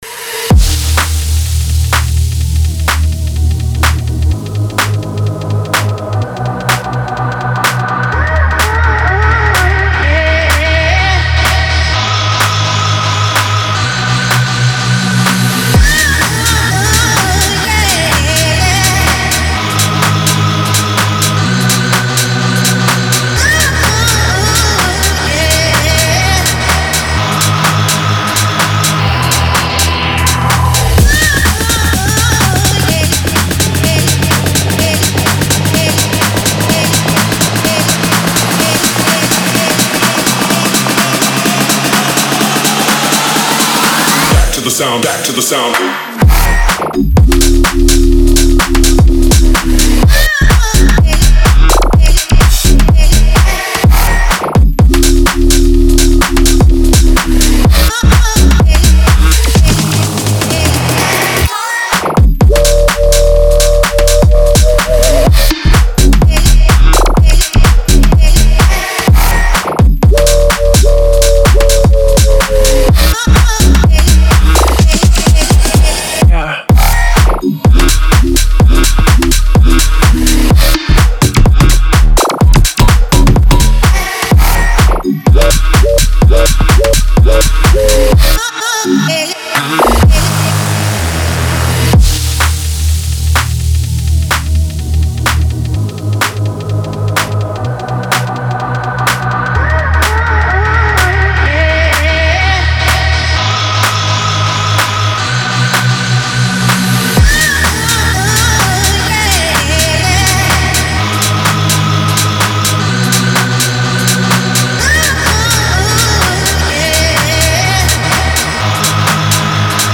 Future House, Euphoric, Happy, Epic, Hopeful